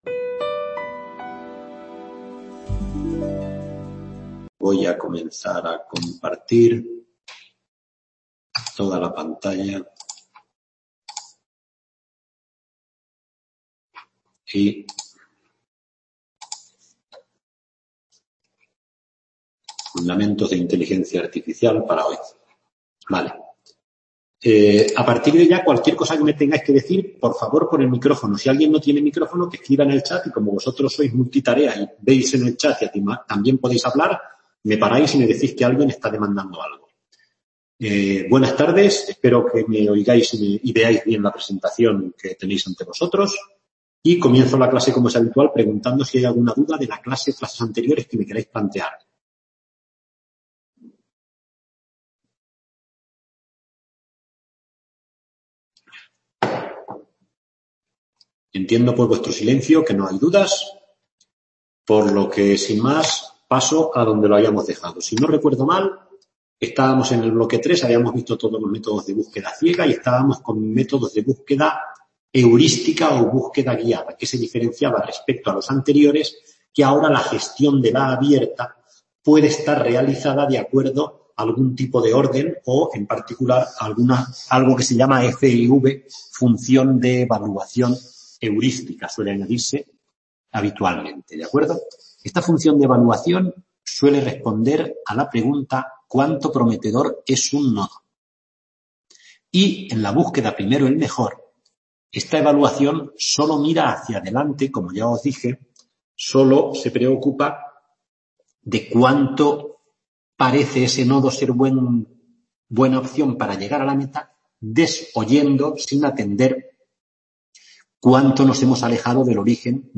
tutoría semanal